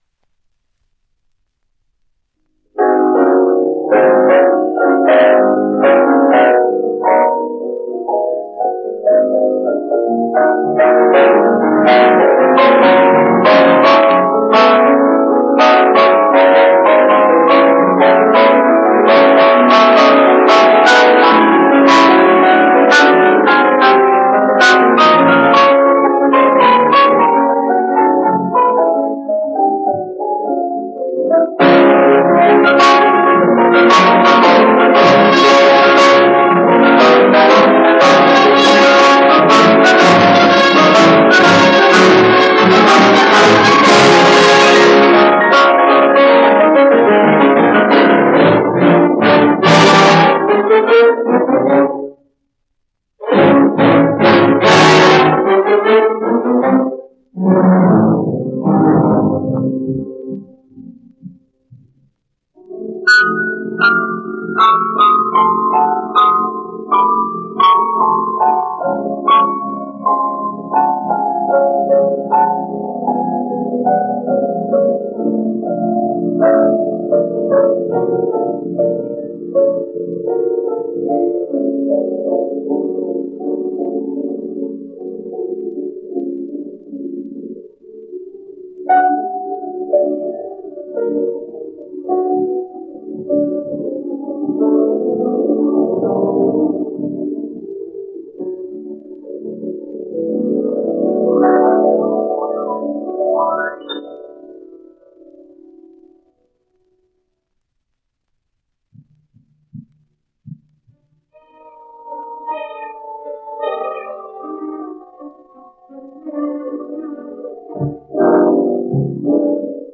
Concerto No. 4, in G minor, Op. 40. Third movement (part 2) (sonido mejorado)